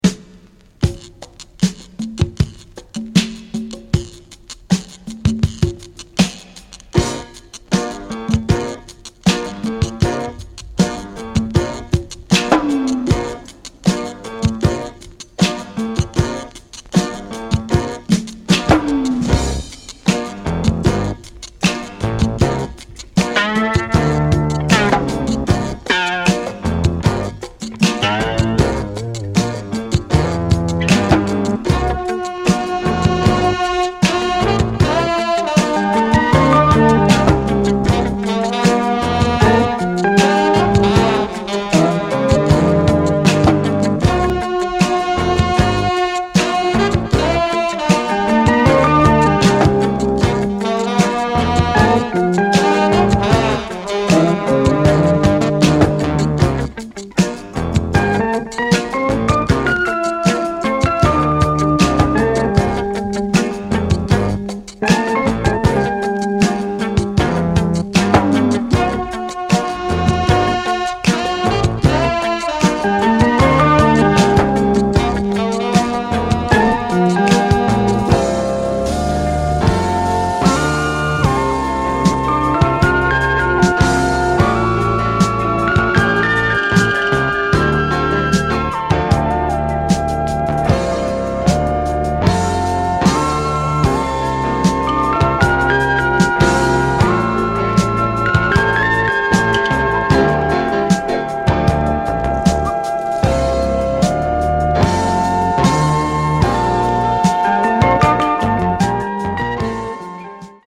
A jazz-fusion must have!